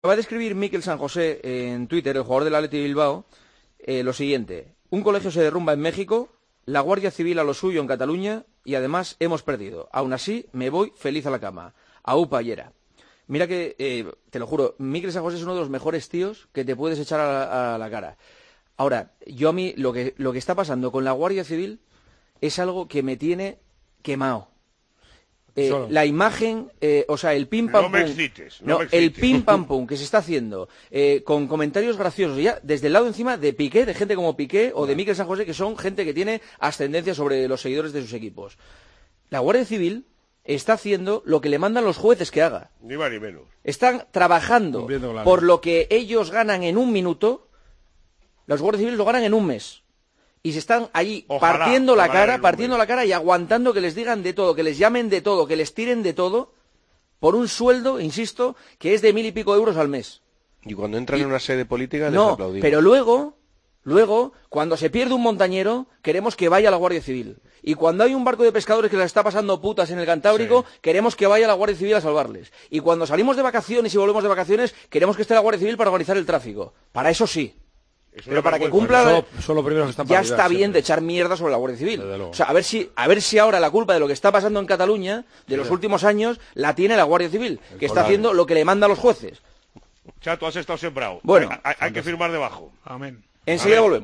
El director de 'El Partidazo de COPE' estalla tras leer un tuit del futbolista Mikel San José en el que resalta algunas “noticias malas” del día